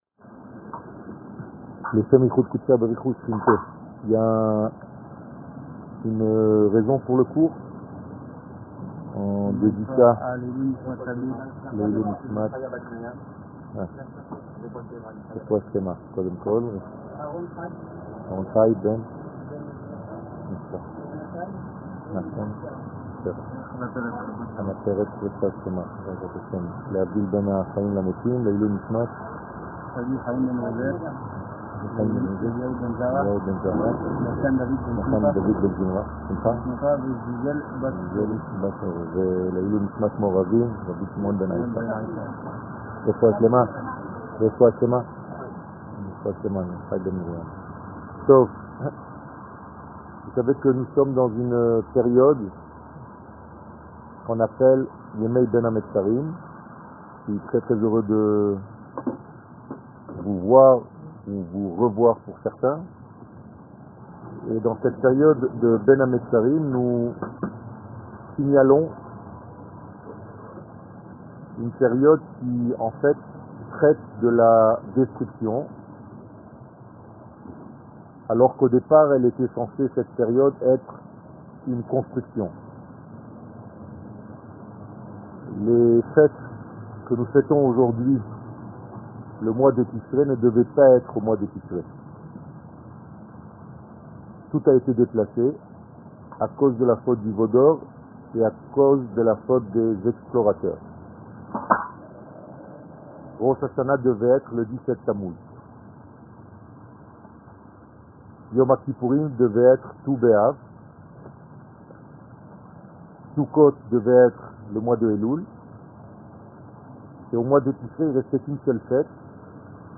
Eretz Israel שיעור מ 17 יולי 2018 59MIN הורדה בקובץ אודיו MP3 (27.37 Mo) הורדה בקובץ אודיו M4A (6.99 Mo) TAGS : 17 Tamouz Tisha b'Av Torah et identite d'Israel שיעורים קצרים